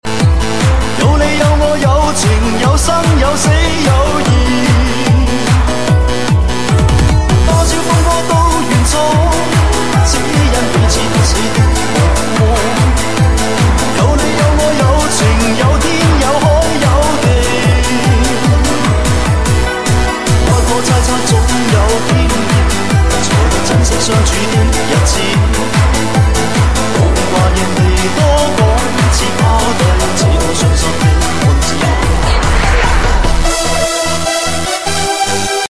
DJ手机铃声